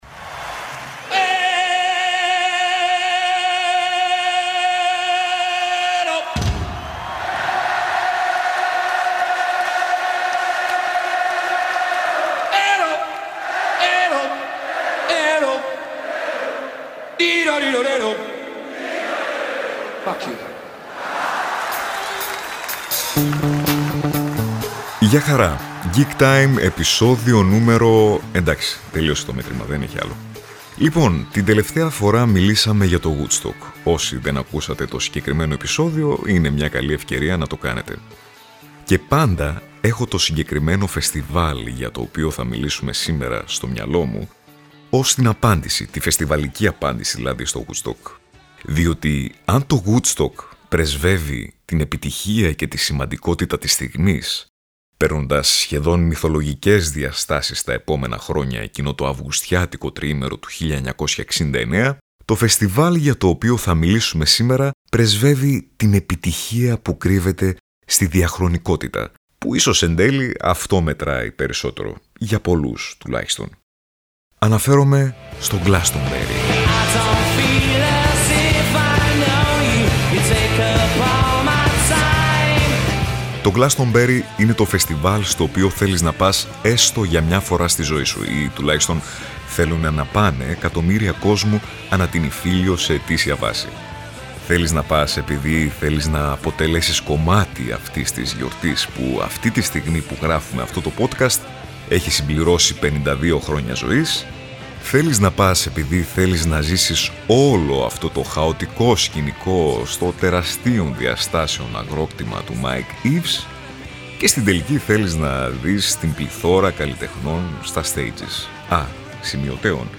Bonus, η συμμετοχή ονομάτων από τον χώρο της μουσικής που θυμούνται και δίνουν την προσωπική τους μαρτυρία από τις πιο δυνατές gig εμπειρίες τους.